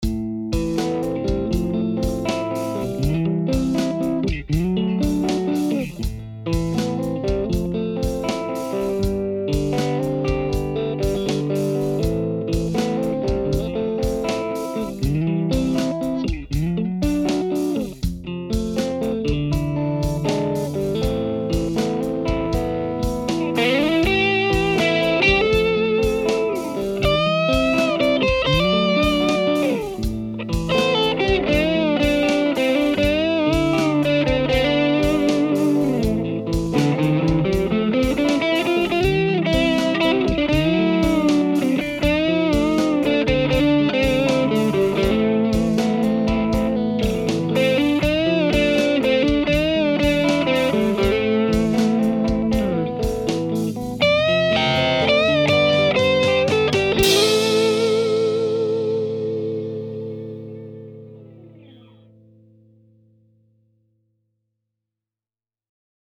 Summary: The Direct Drive is a nice, fairly transparent overdrive that maintains your amp’s tone that can produce mild grit to over-the-top, searing gain that sustains for days.
The rhythm part features a Strat. The Direct Drive’s volume is at unity, tone is dead-center, and Drive is about 10 am to get just a tiny bit of overdrive.
This produces a nice, smooth grind, and lots of sustain.
By the way, the cool thing about that clip is that it was recorded at bedroom level, with my ribbon mic about 2 inches from the grille cloth!